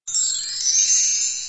reward.wav